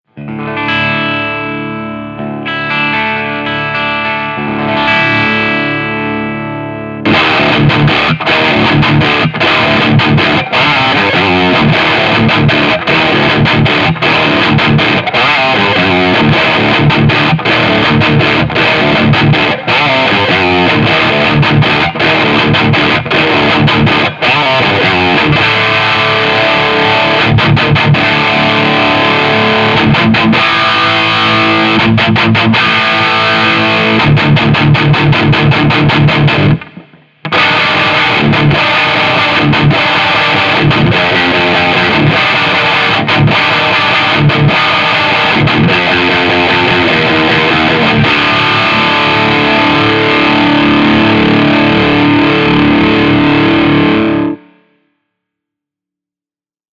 Le crapuleux grain de la marque British est émulé ici avec brio, bien que je m’attendais à un peu moins de gain je dois dire !
Ca reste bien définit avec la plupart des micros qui équipent mes guitares malgré la quantité de gain, avec une préférence au single coil (bien mordant !) et P90 (et wide range).
Idéale pour les leads massifs et solo plein de sustain, l’édition limitée Crush the Button est une superbe overdrive high gain typée, à la fois rugueuse et pleine d’harmoniques délectables qui s’imposera sur votre board pour envoyer la sauce anglaise !